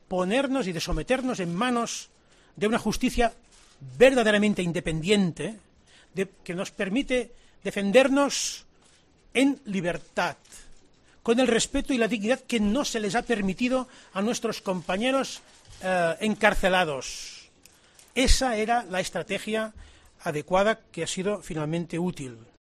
"Aquí hemos tenido el respeto y la dignidad que no se ha permitido a los compañeros encarcelados", señaló Puigdemont en rueda de prensa un día después de que el Tribunal Supremo retirase las euroórdenes que pesan sobre él.